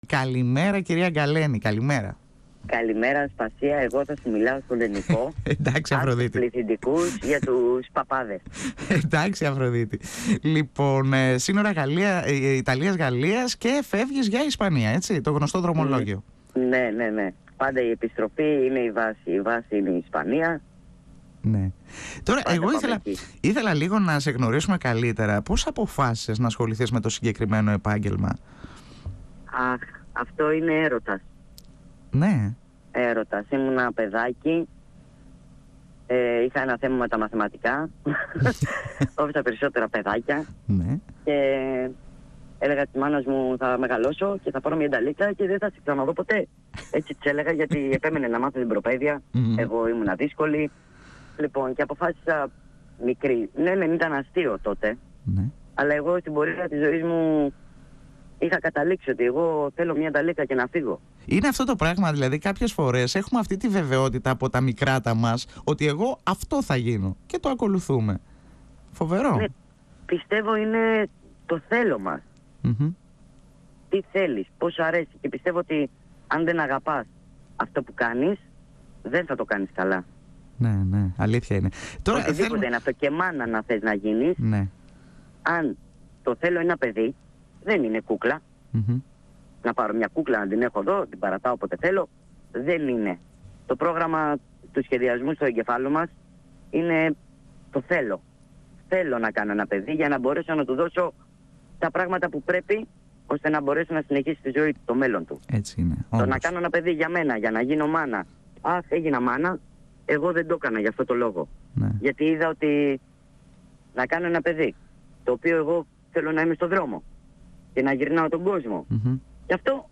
Εδω και Τωρα Συνεντεύξεις